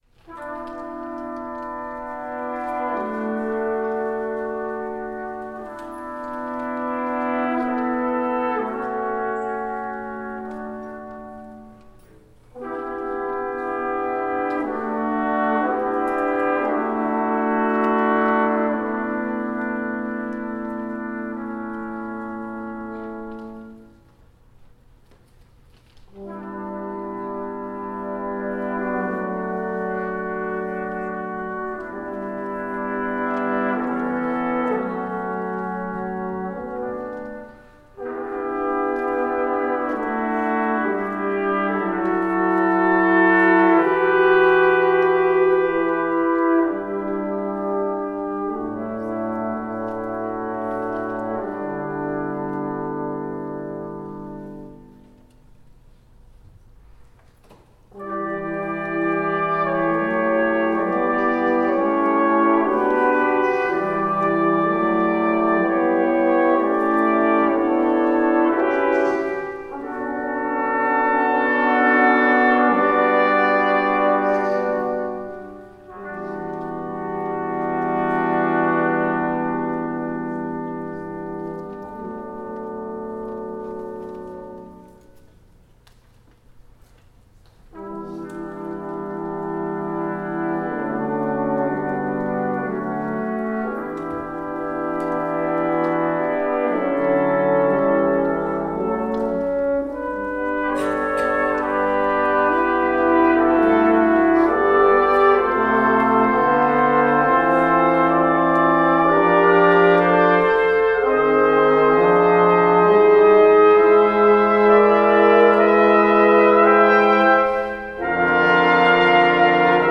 A three movement brass quintet chonicling the journey from dusk, through midnight, to dawn.
Instrumentation: Tp, Flg, Hn, Tbn, B.Tbn Composition Date: January 2014 Download the score Listen to midi recordings: I II III Listen to live recordings: I II III